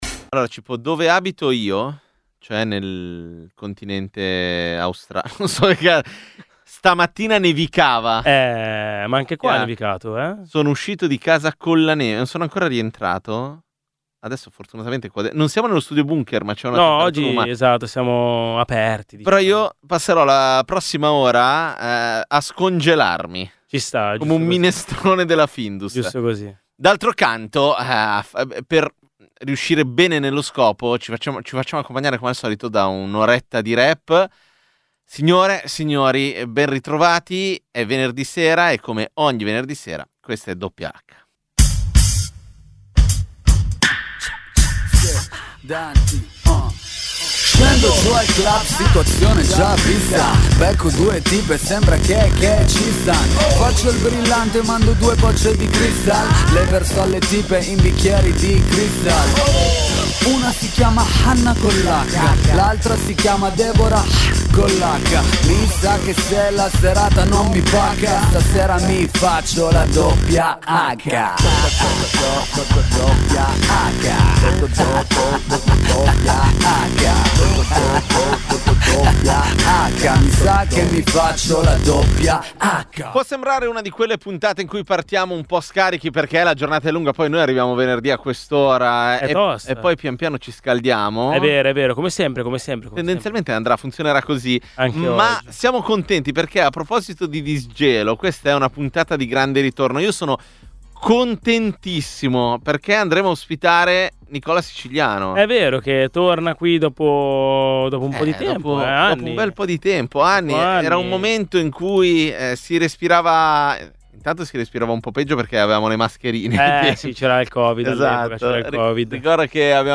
Dal 2011 è la trasmissione dedicata all’hip-hop di Radio Popolare.